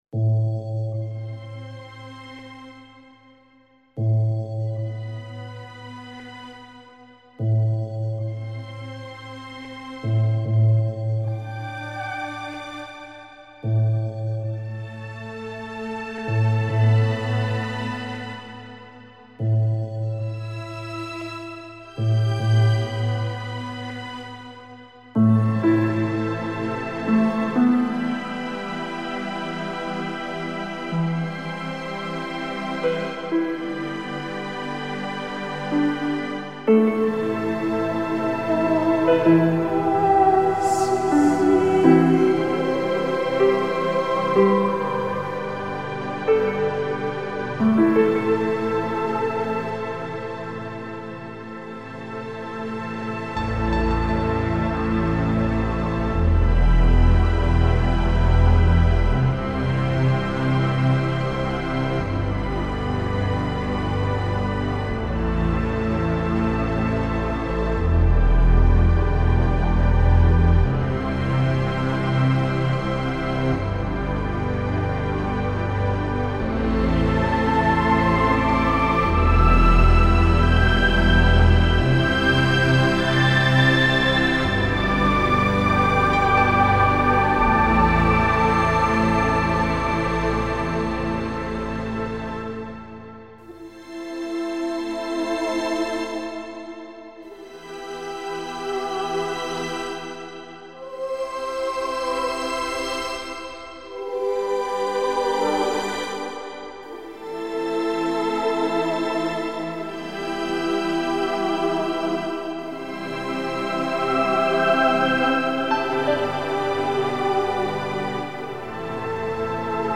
•  3:20 2008 klassinen